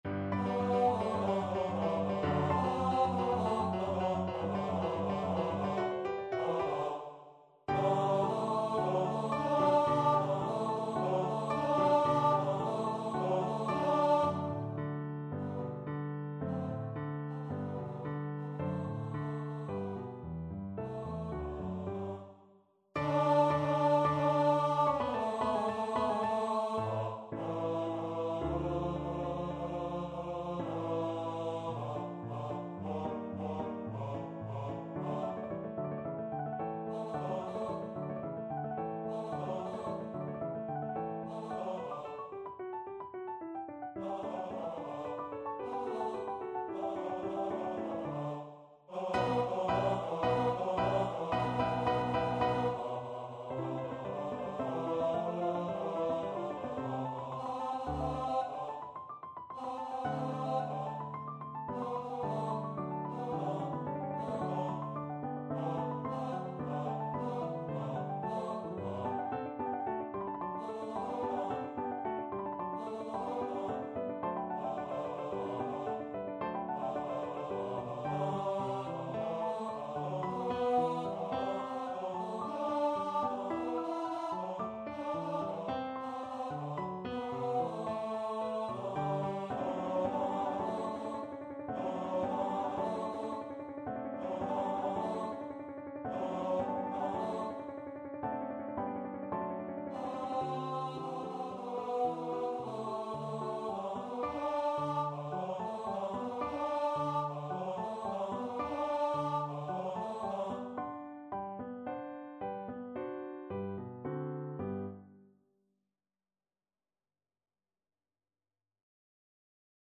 4/4 (View more 4/4 Music)
Allegro assai =220 (View more music marked Allegro)
Classical (View more Classical Bass Voice Music)